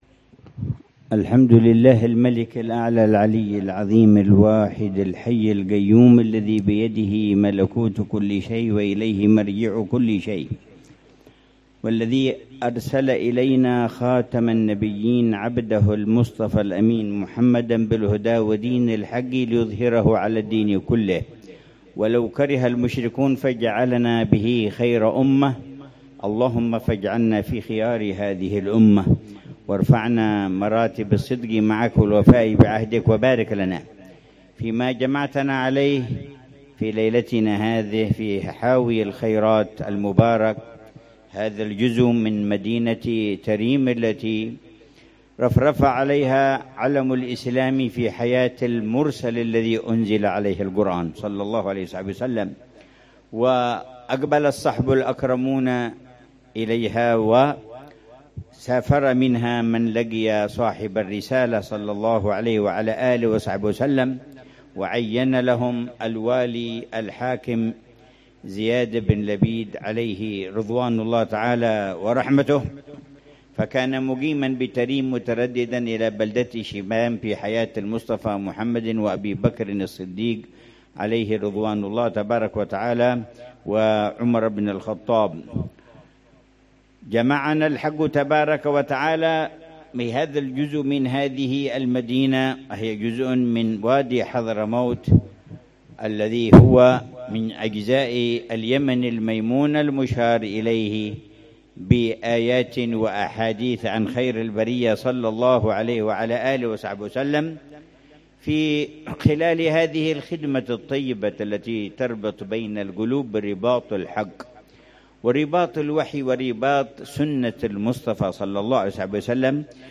كلمة الحبيب عمر بن حفيظ في دار الكتاب والسنة وعلومهما 1447هـ
كلمة العلامة الحبيب عمر بن حفيظ في دار الكتاب والسنة وعلومهما، بحاوي الخيرات، مدينة تريم، ليلة الأحد 6 ربيع الثاني 1447هـ